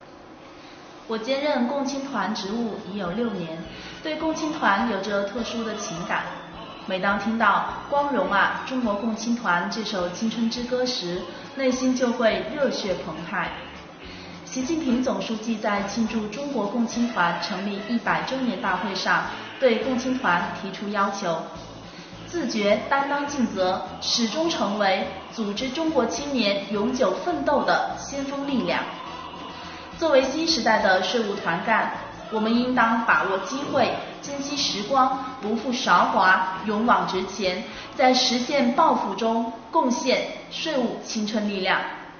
建团百年，听听来宾税务青年的心里话......